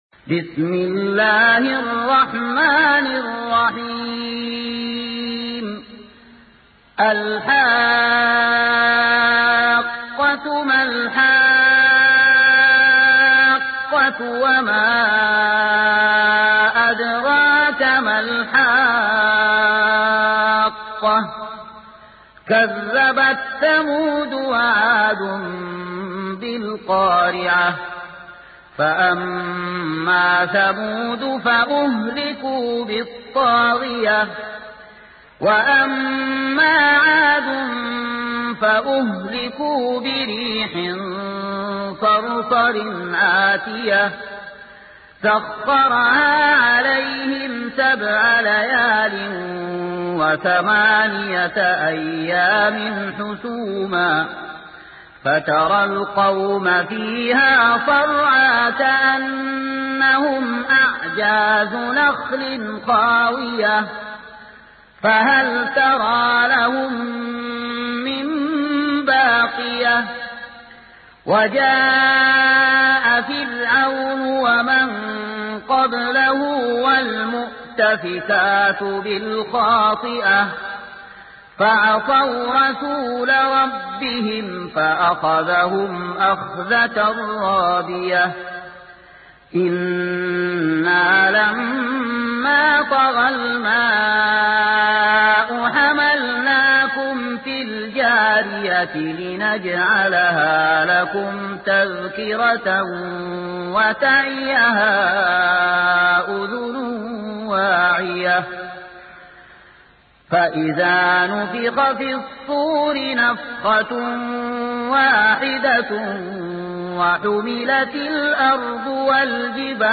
سورة الحاقة | القارئ